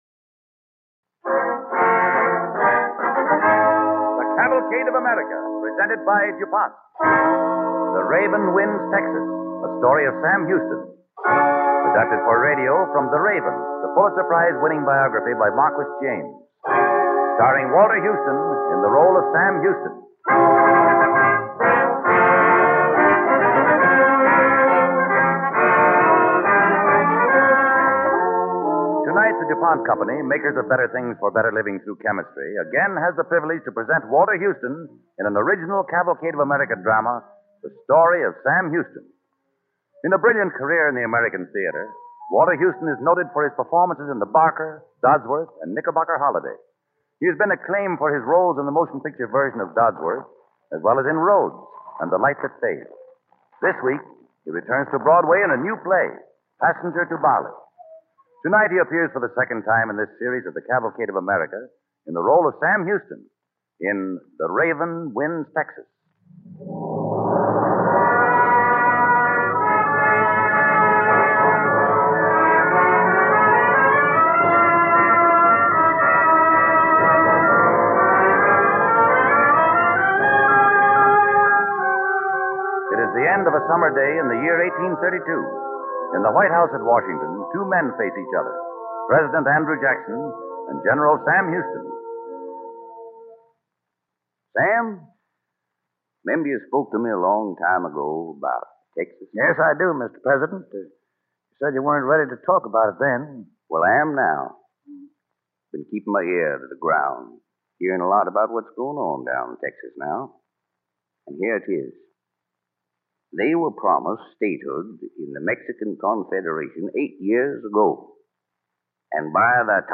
The Raven Wins Texas, starring Walter Houston